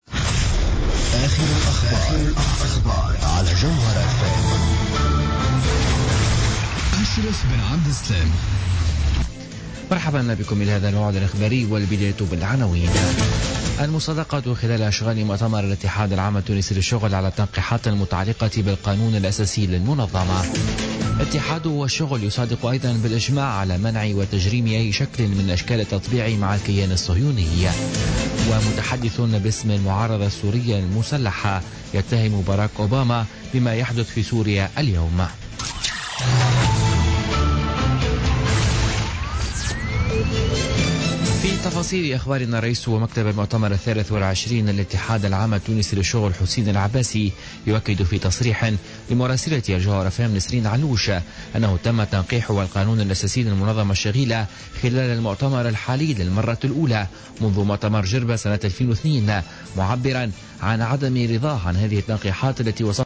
نشرة أخبار منتصف الليل ليوم الاربعاء 25 جانفي 2017